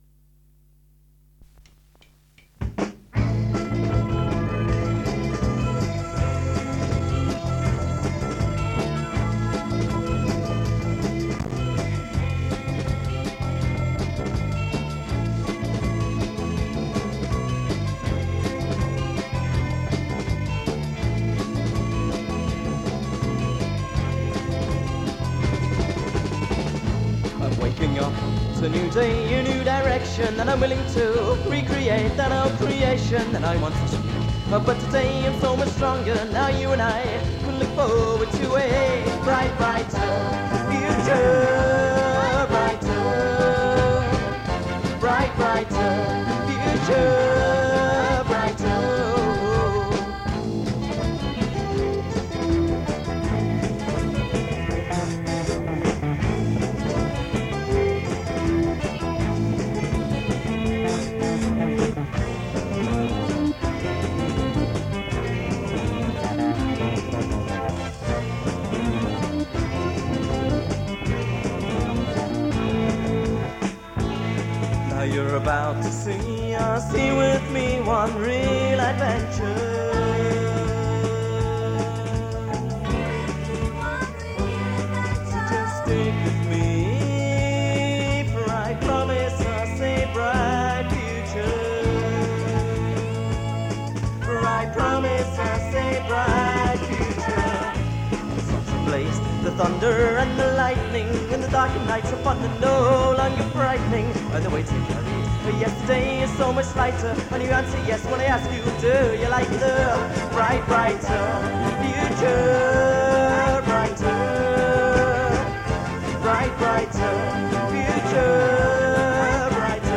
Guitar
keyboards
Drums